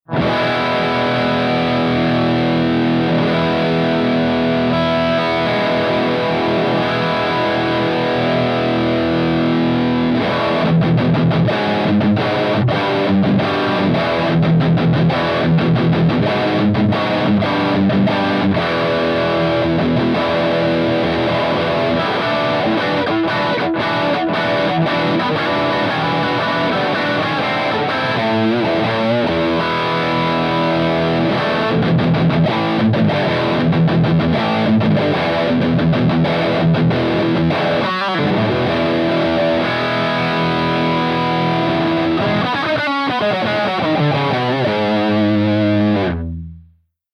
143_ROCKERVERB_CH2HIGHGAIN_V30_P90